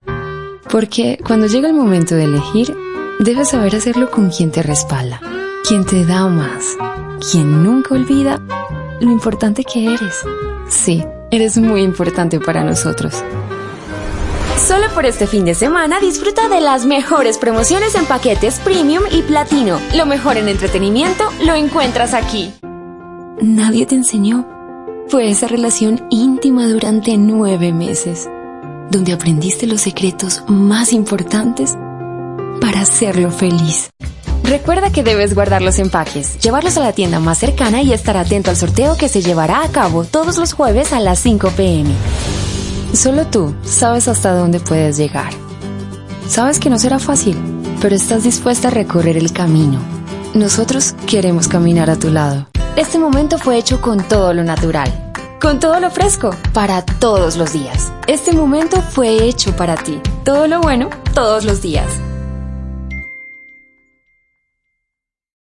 Demo comercial
Voz versátil y expresiva, hablante nativa de español.
Tono neutro latinoamericano y acentos colombianos.
Tono: Medio (Natural) y Alto-Bajo Opcional.
Acentos: Español colombiano nativo y español neutro LATAM.